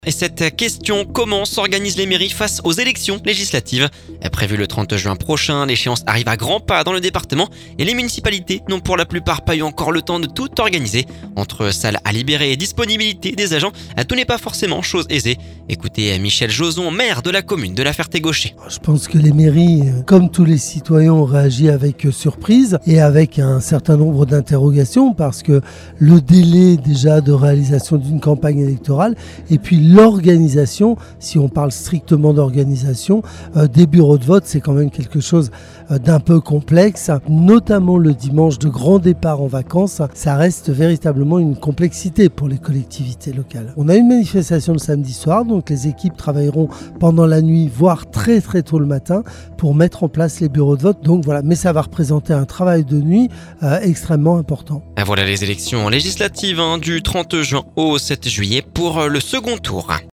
Michel Jozon, maire de la Ferté-Gaucher…